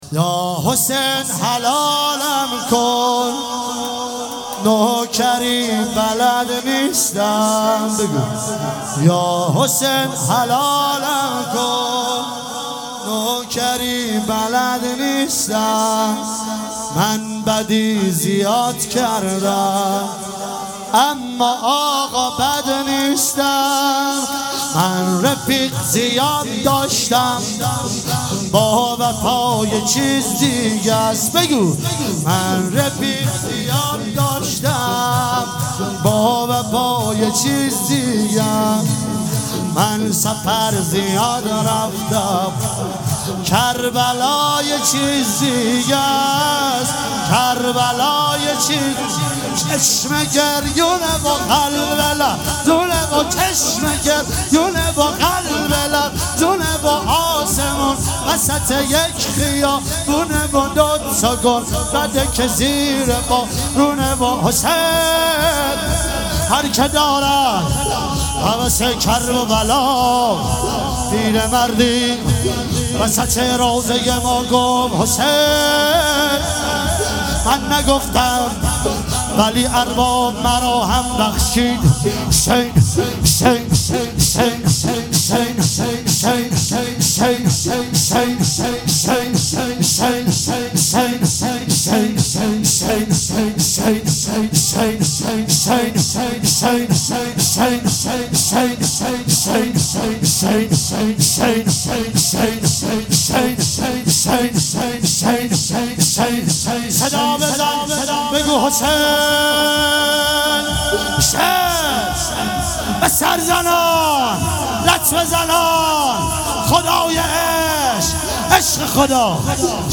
فاطمیه اول ۱۳۹۶
کرمانشاه
شور یاحسین حلالم کن